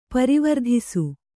♪ parivardhisu